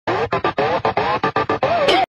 Goofy Ahh Speedy Music